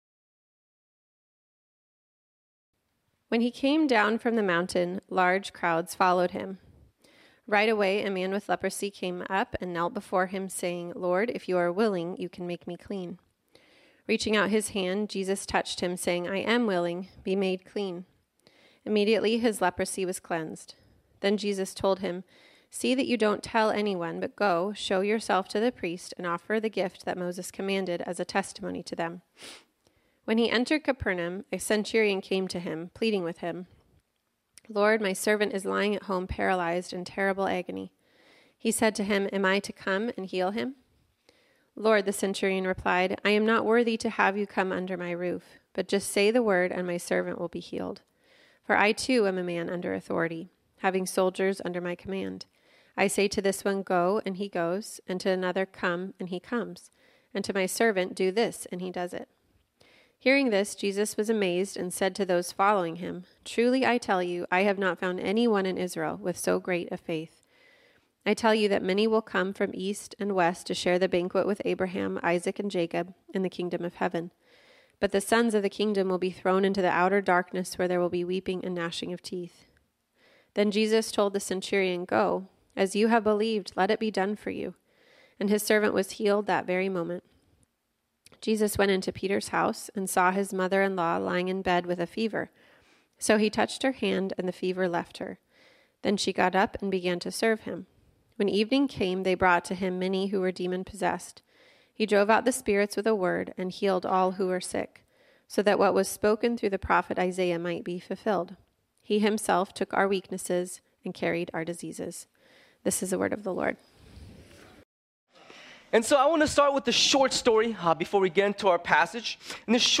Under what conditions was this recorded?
This sermon was originally preached on Sunday, April 7, 2024.